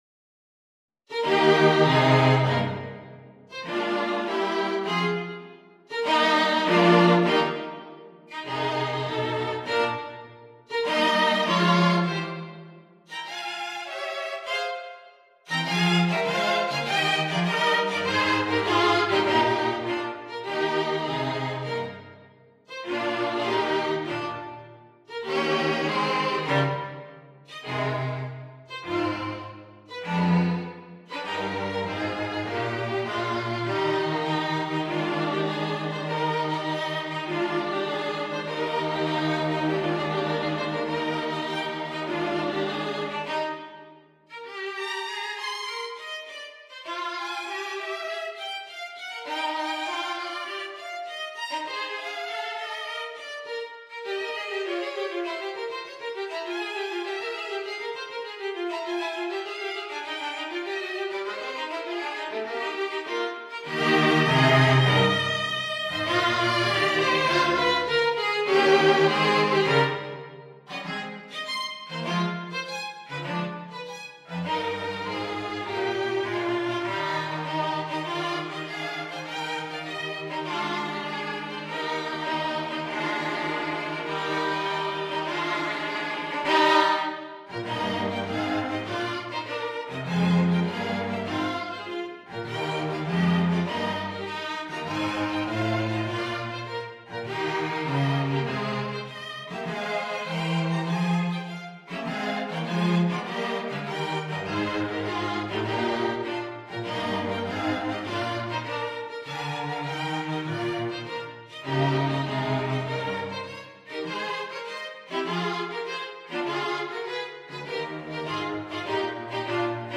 Violin 1Violin 2ViolaCello
Classical (View more Classical String Quartet Music)
haydn_string_quartet_op76_6_STRQ.mp3